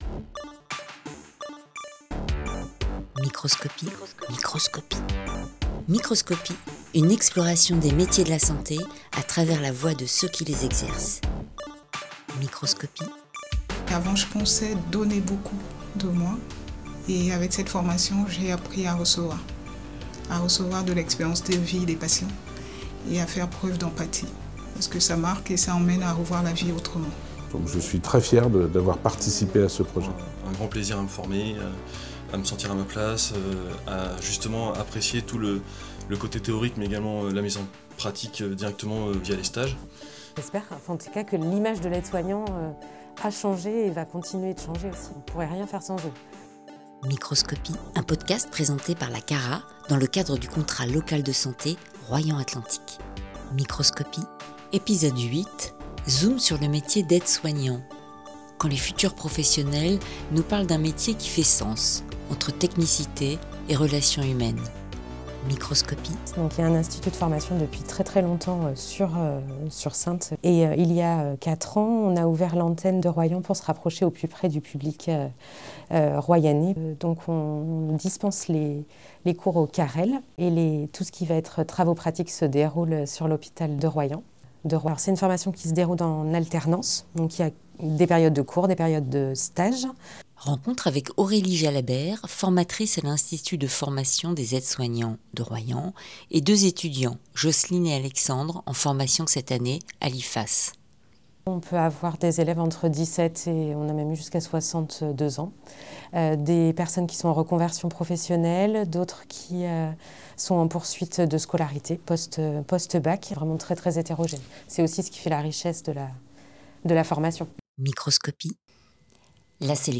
À travers des témoignages sincères de professionnels en poste ou en formation sur le territoire de Royan Atlantique, partez à la découverte d’un univers aussi passionnant que méconnu.